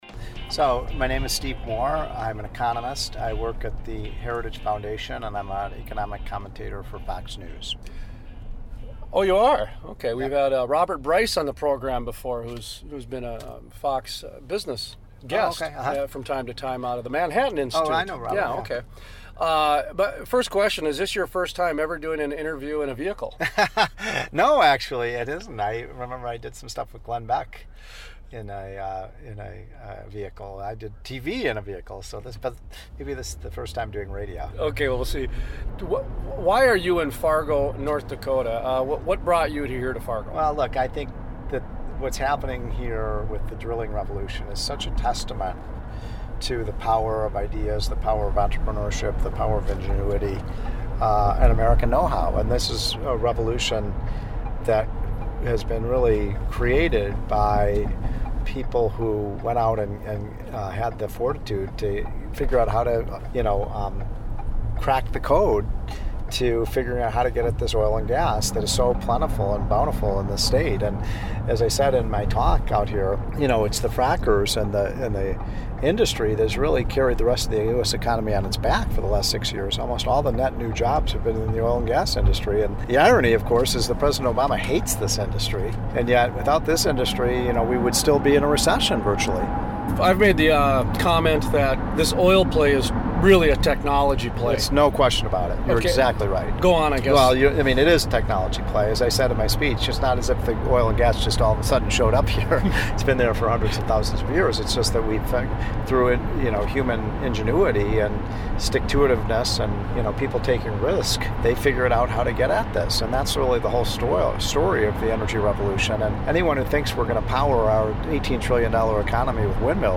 In this Decade of Discussion, The Crude Life looks back at our interview with Stephen Moore, Writer and Commentator.